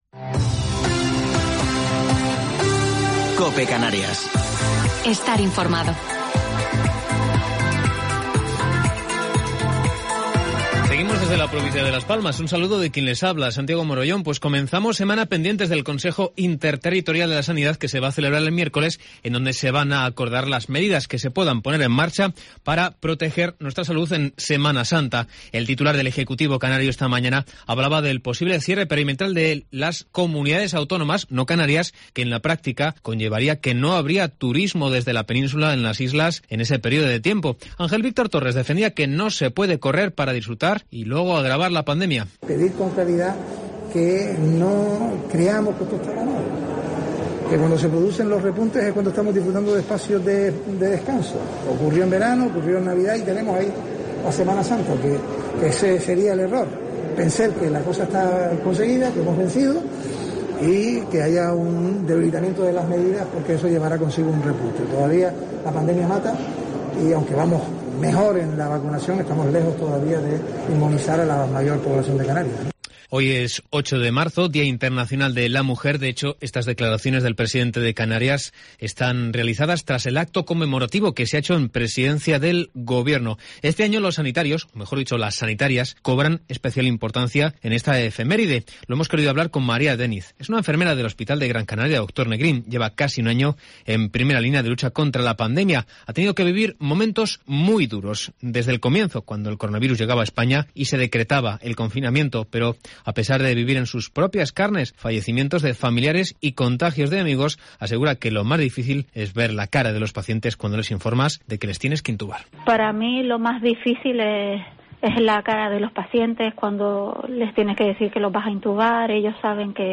Informativo local 8 de Marzo del 2021